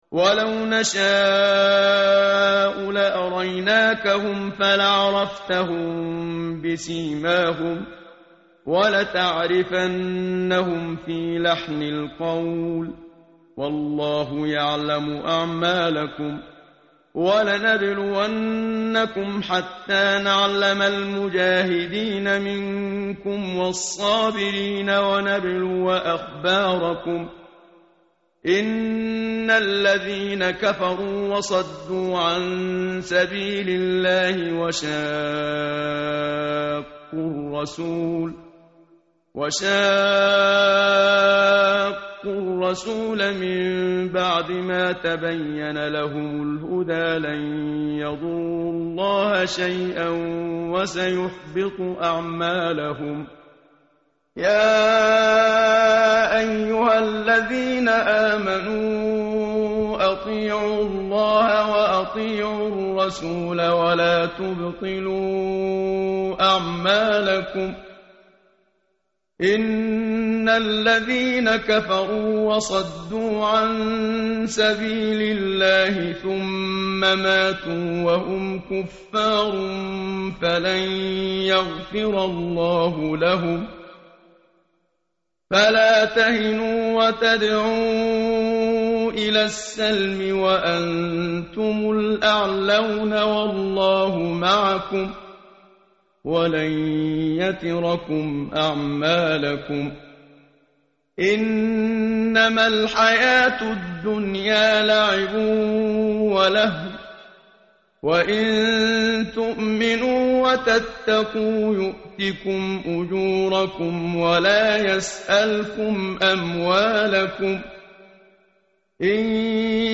tartil_menshavi_page_510.mp3